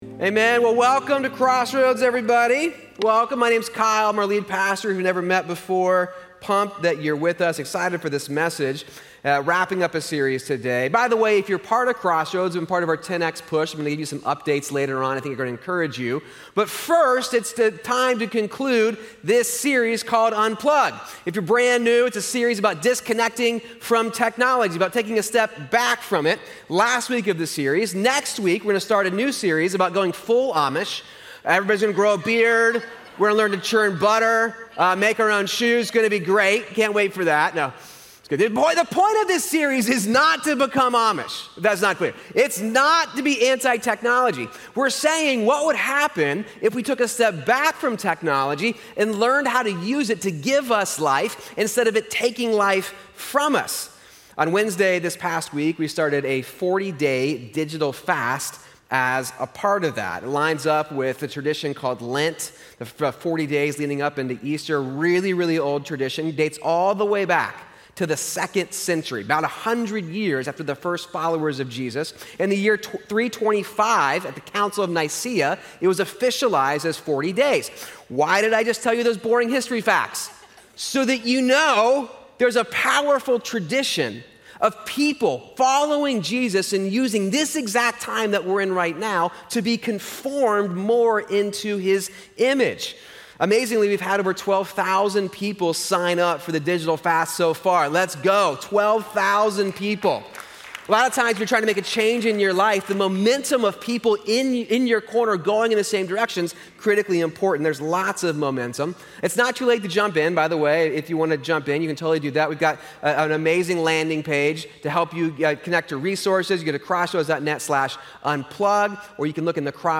You can’t change the world if you don’t have good fuel. Recorded live at Crossroads Church in Cincinnati, Ohio.